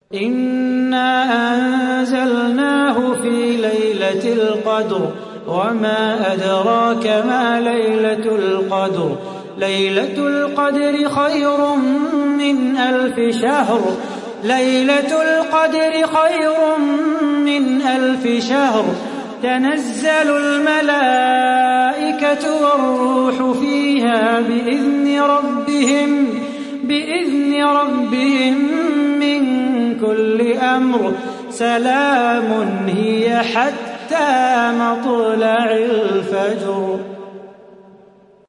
Kadir Suresi mp3 İndir Salah Bukhatir (Riwayat Hafs)